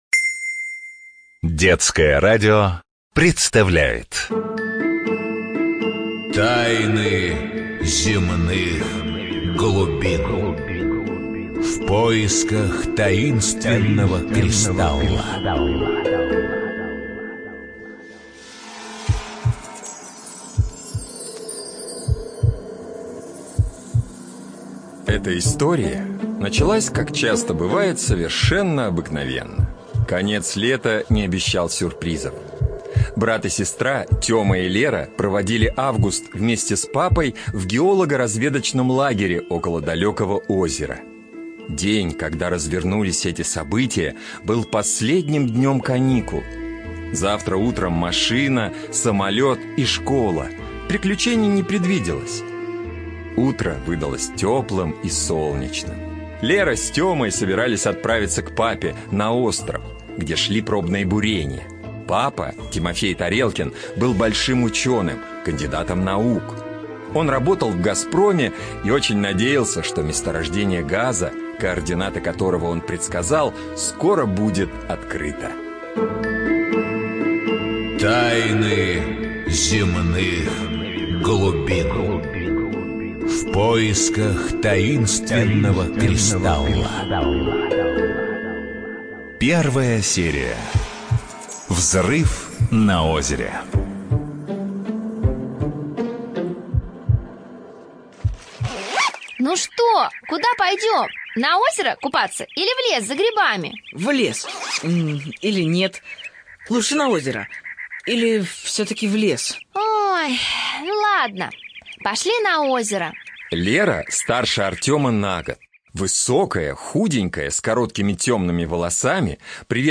ЖанрРадиопрограммы